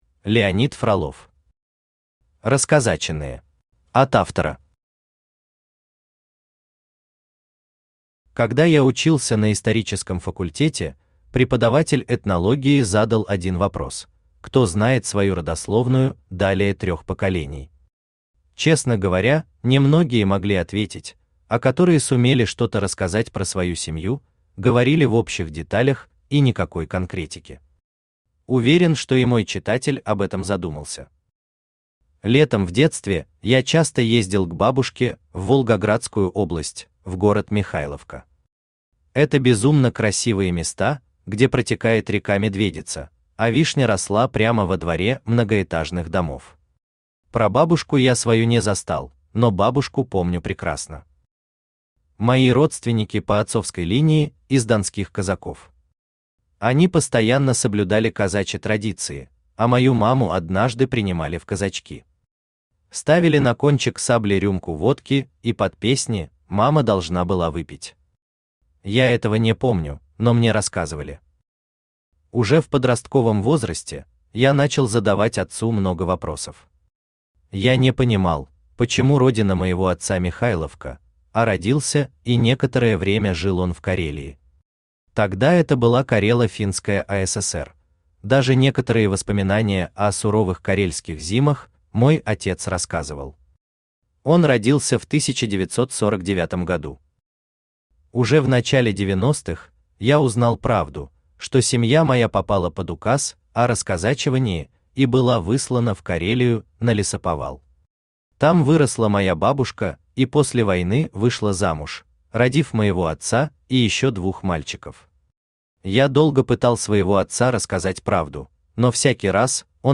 Аудиокнига Расказаченные | Библиотека аудиокниг
Aудиокнига Расказаченные Автор Леонид Фролов Читает аудиокнигу Авточтец ЛитРес.